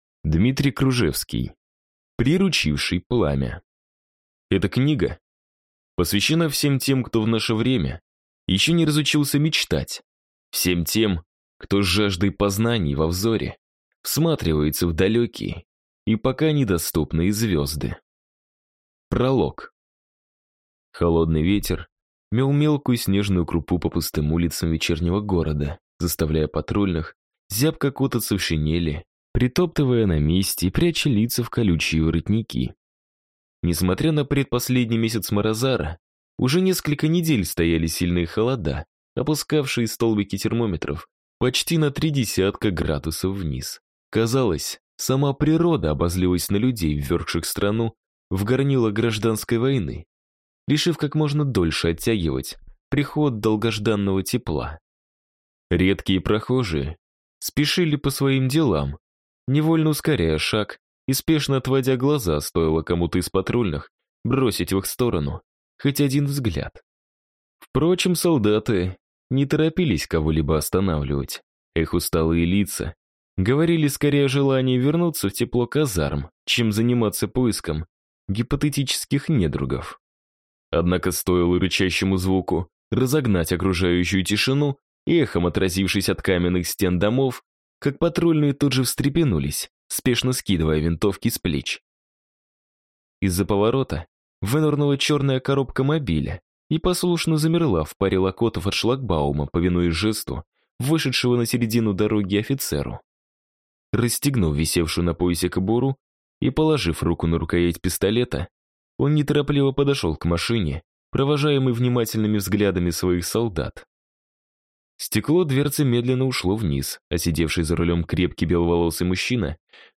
Аудиокнига Тихий шепот звезд. Часть 2. Приручивший пламя | Библиотека аудиокниг